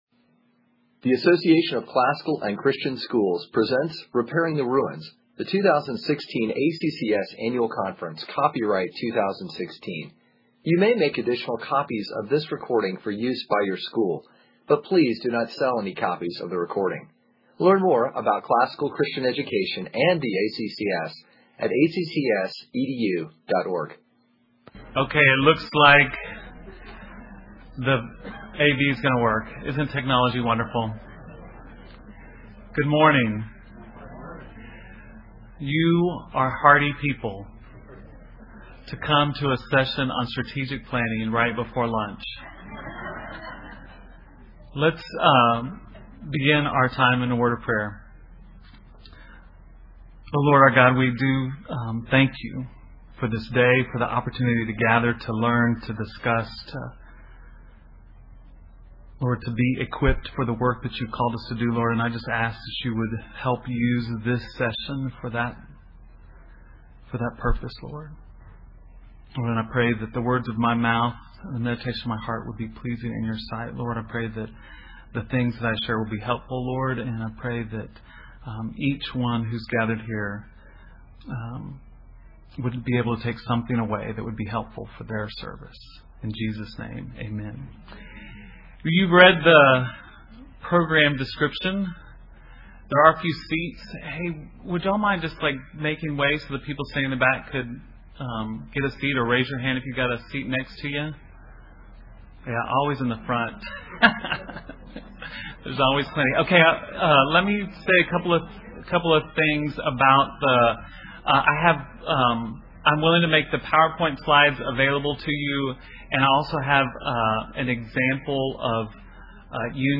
2016 Workshop Talk | 055:16 | Fundraising & Development, Leadership & Strategic